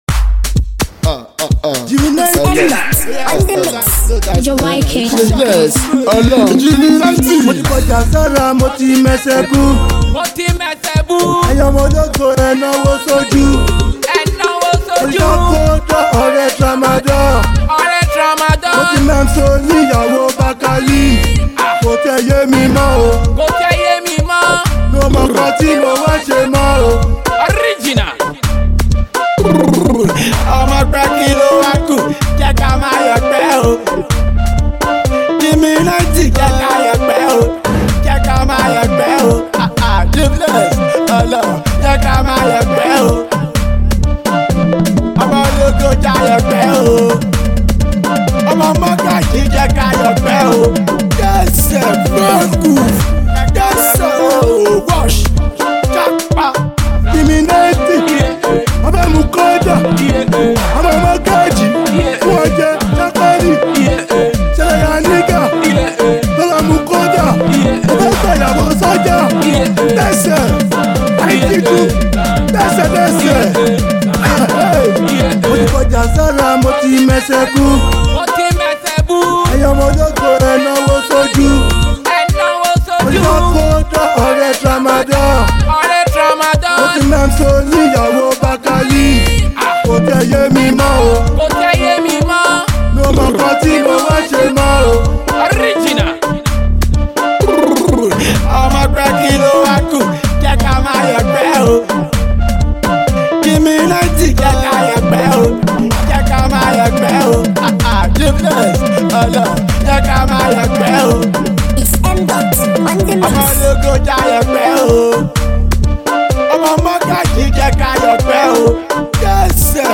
motivation song